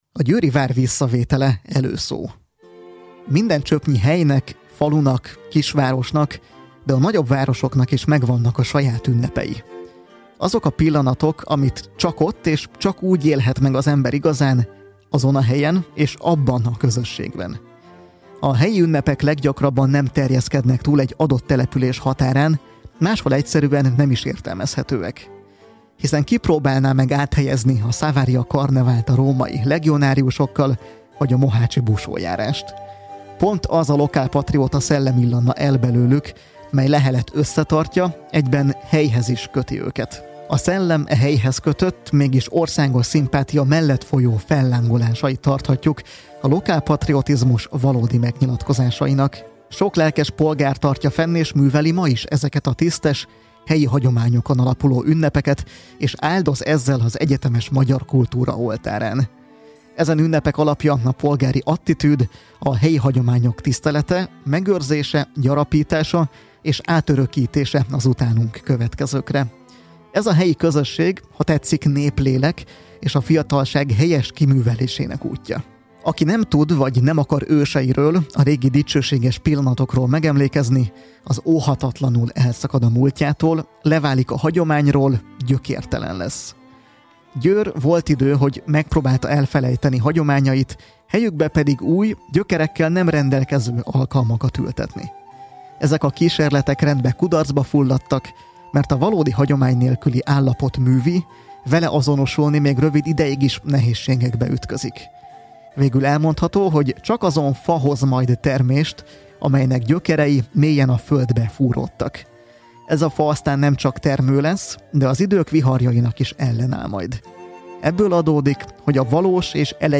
Beszámoló a „Győr visszavétele” dráma rádiójátékként történt bemutatásáról
Gyor-visszavetele-radiojatek.mp3